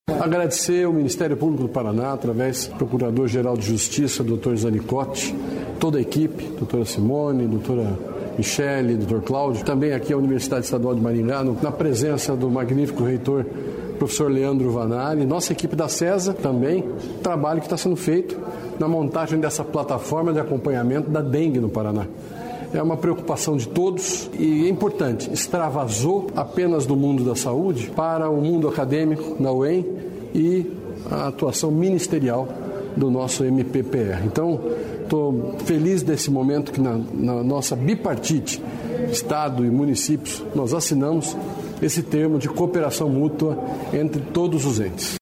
Sonora do secretário de Estado da Saúde, Beto Preto, sobre o painel da dengue